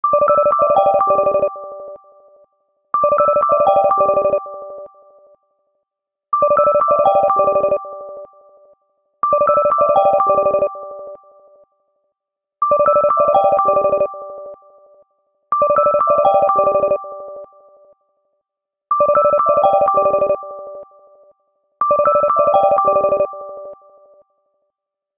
lg-space-signal_24591.mp3